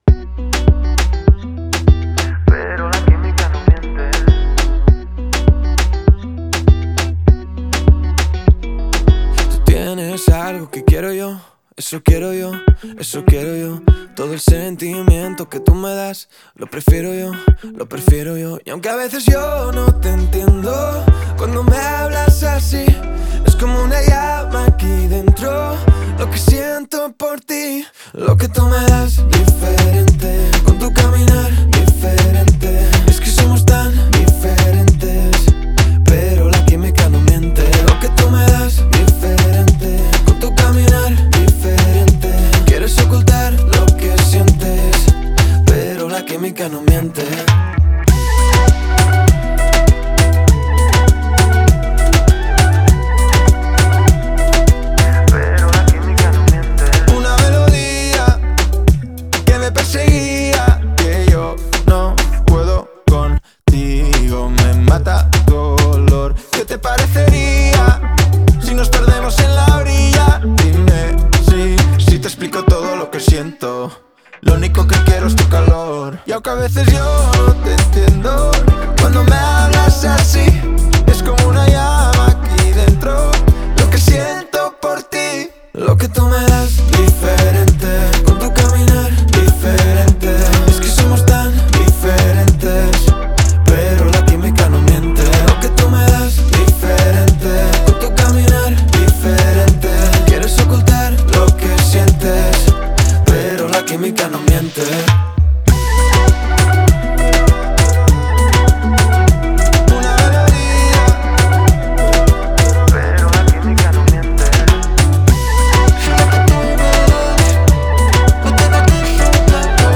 это яркая и зажигательная песня в жанре латин-поп